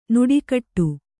♪ nuḍi kaṭṭu